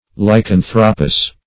Lycanthropous \Ly*can"thro*pous\, a.
lycanthropous.mp3